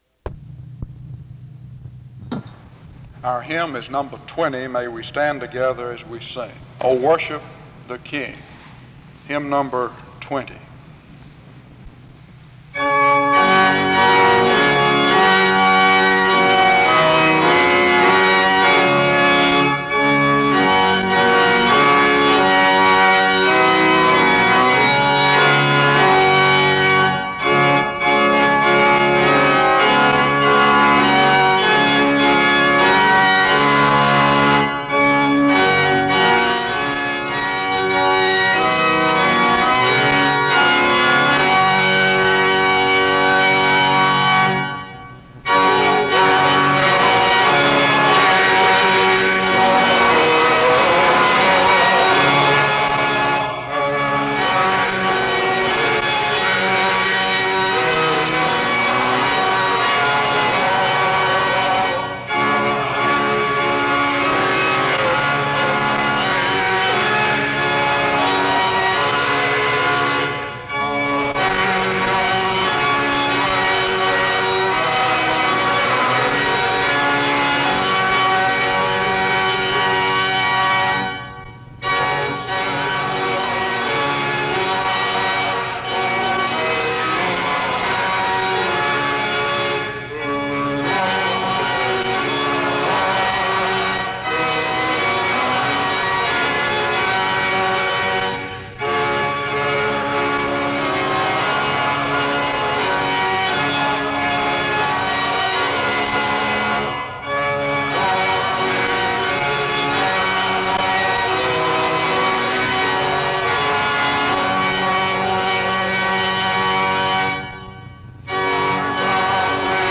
The service starts with the singing of the hymn “O Worship the King” from 0:00-3:18. A responsive reading takes place from 3:22-5:21. A prayer is offered from 5:23-6:42.